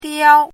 chinese-voice - 汉字语音库
diao1.mp3